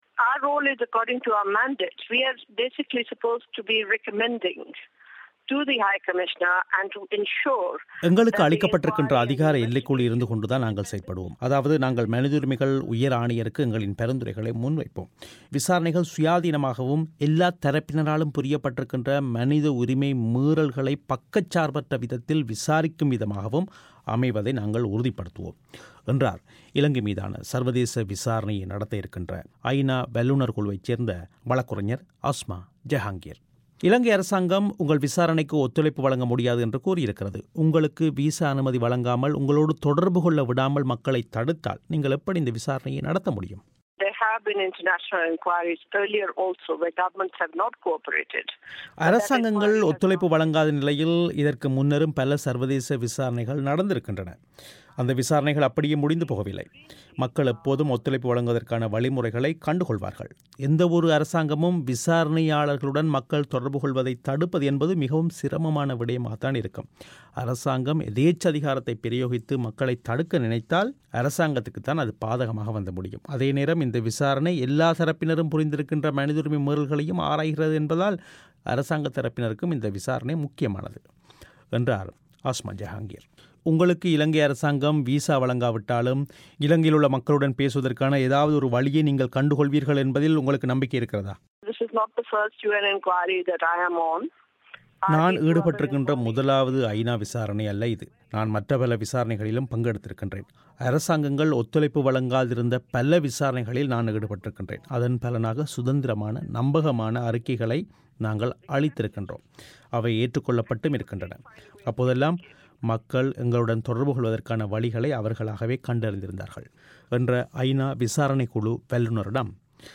இலங்கை மீது சர்வதேச விசாரணை நடத்தவுள்ள ஐநா விசாரணைக் குழுவுடன் தொடர்புகொள்ள விடாமல் மக்களைத் தடுப்பது அரசாங்கத்துக்கே பாதகமாக அமையும் என்று விசாரணைக் கழு வல்லுநர் அஸ்மா ஜெஹாங்கிர் பிபிசியிடம் கூறினார்